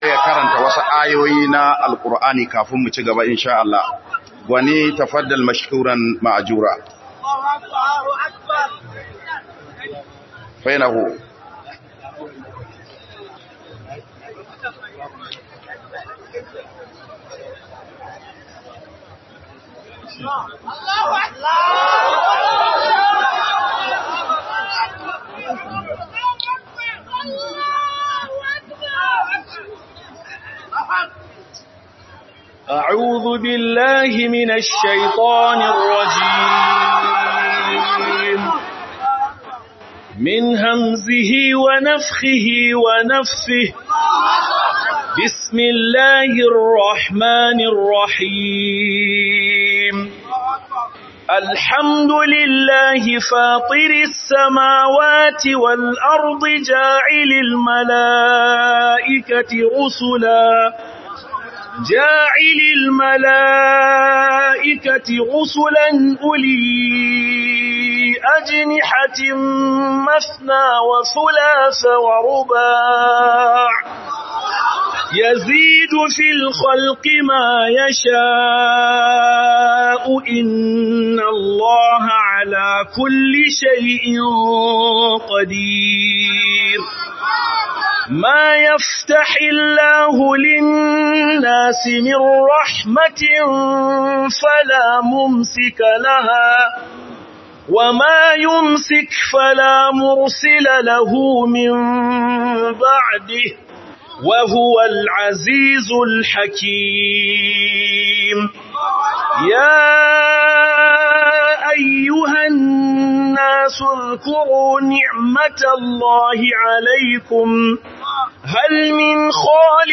Mudage Da Rokon Allah || Ghana - MUHADARA